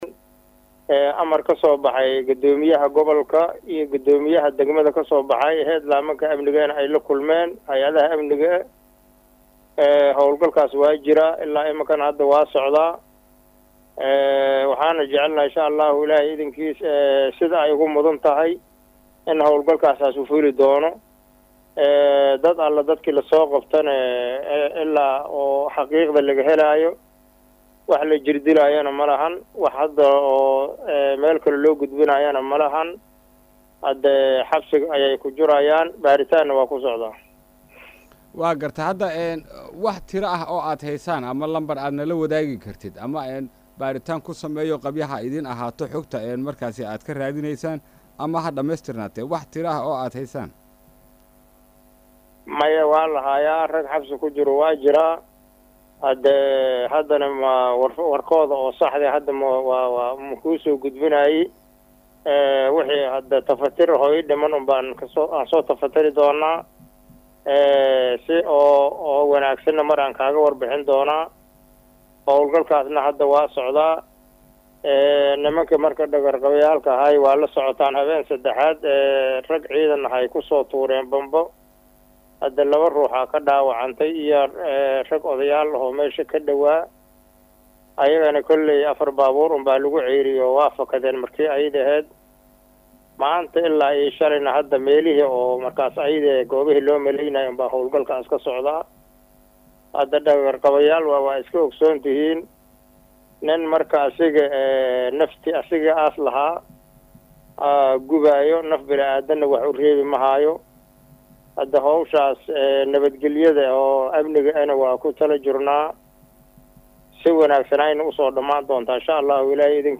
Guddoomiye ku xigeenka Gobalka Hiiraan ee dhinaca Siyaasadda iyo Amniga Muxumud Cali Axmed oo la hadlay Radio Muqdisho codka Jamhuuriyadda